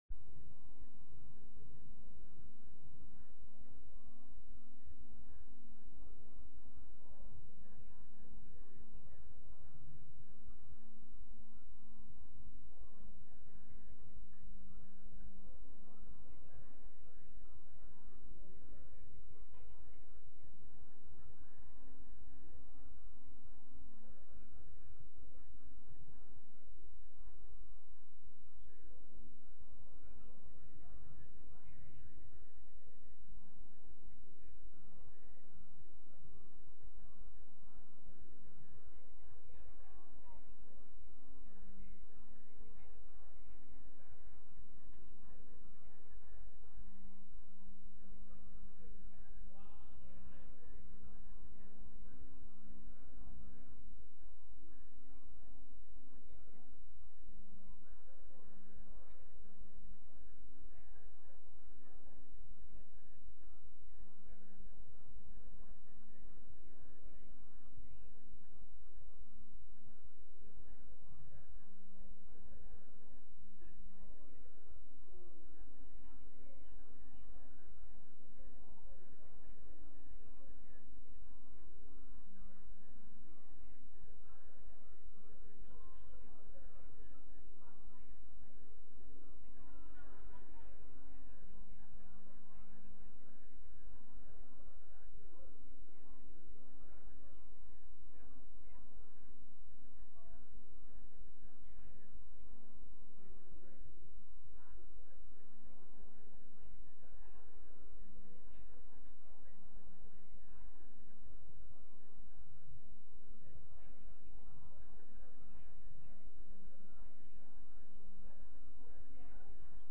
From Series: "Sunday Worship"
Sunday-Service-6-26-22.mp3